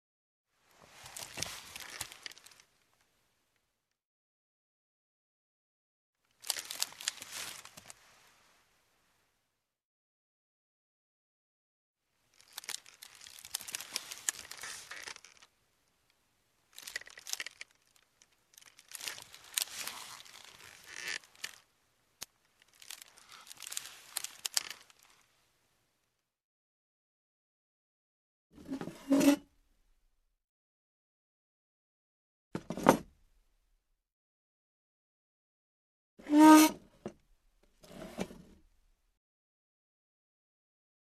Мебель звуки скачать, слушать онлайн ✔в хорошем качестве